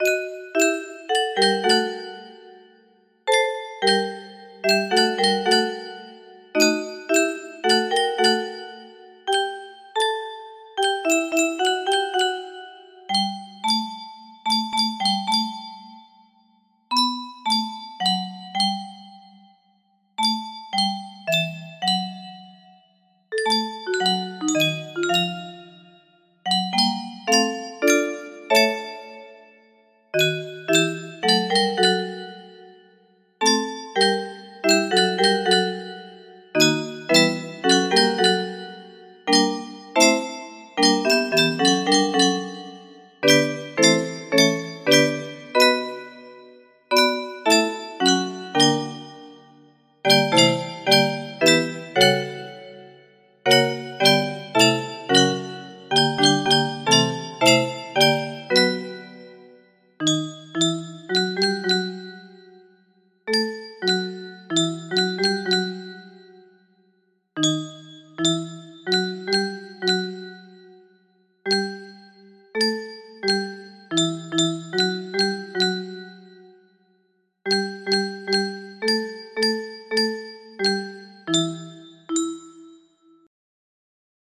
Reverie music box melody
Full range 60